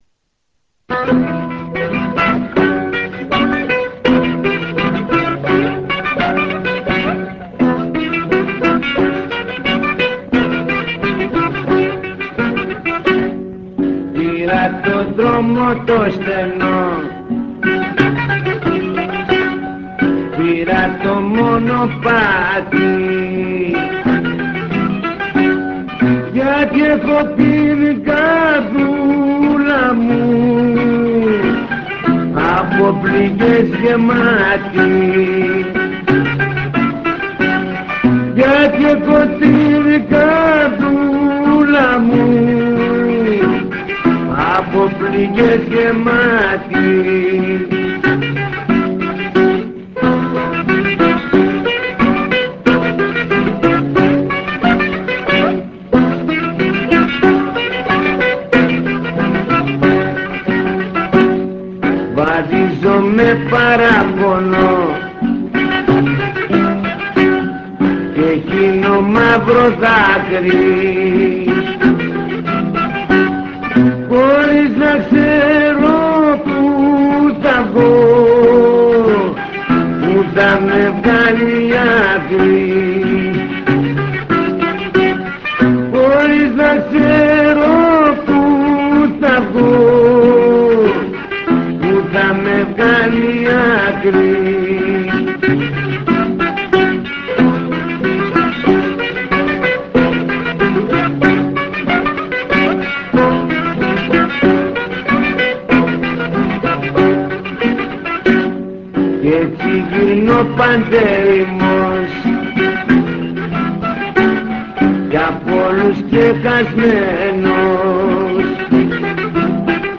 Rebetiko